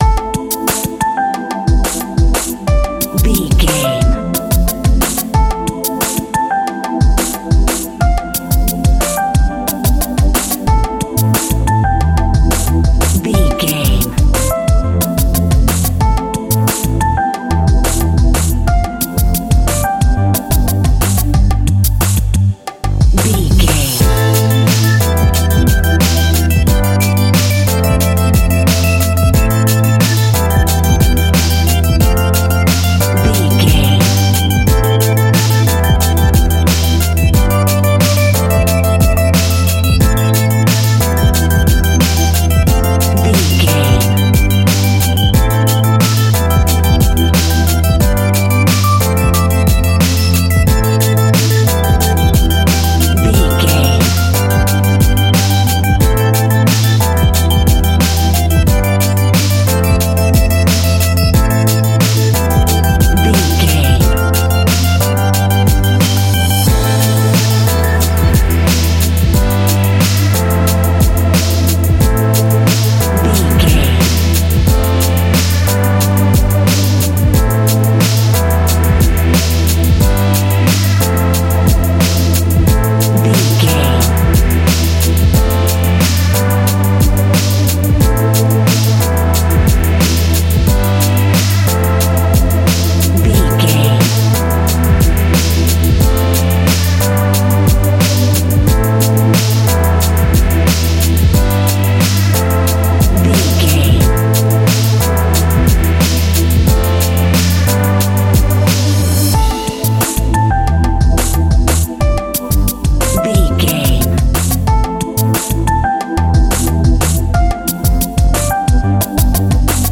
Aeolian/Minor
hip hop
hip hop instrumentals
downtempo
synth lead
synth bass
synth drums
turntables